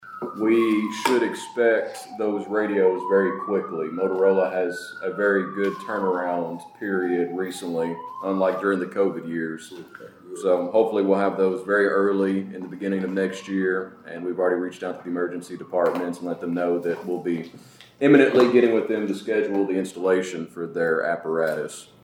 Caldwell County Fiscal Court heard updates on proposed and ongoing community projects during a brief meeting on Tuesday morning.